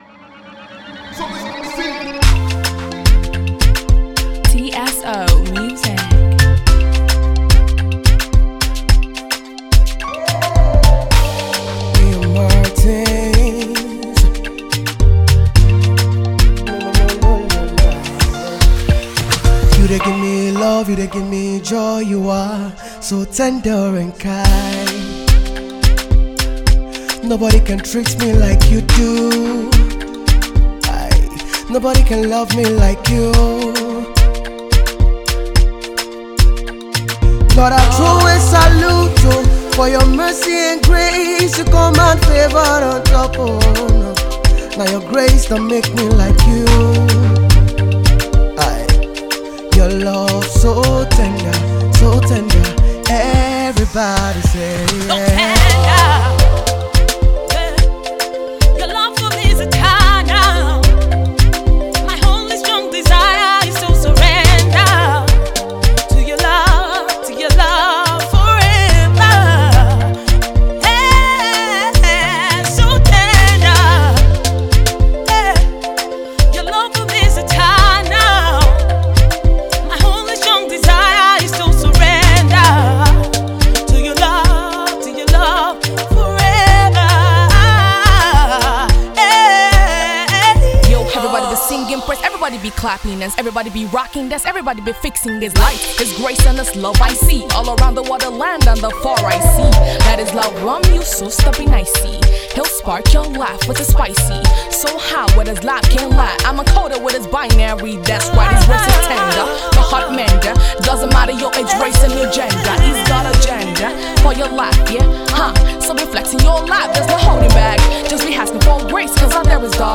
a dope Afrobeat tune